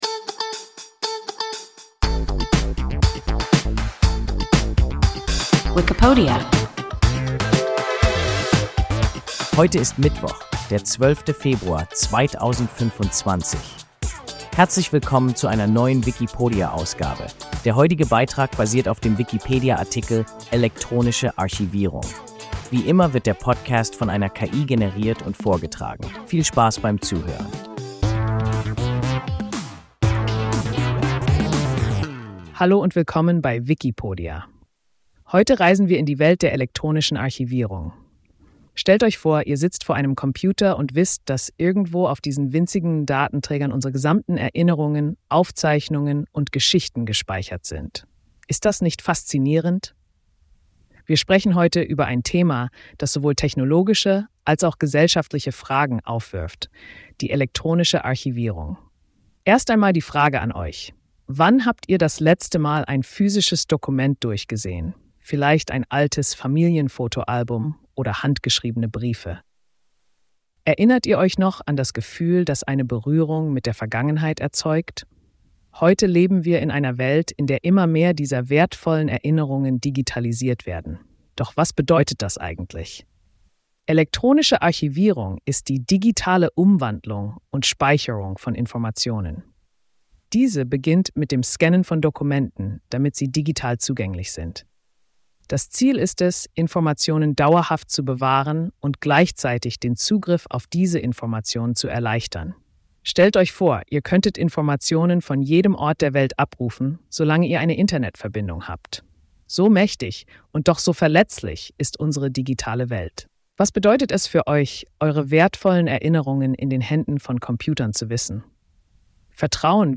Wikipodia – ein KI Podcast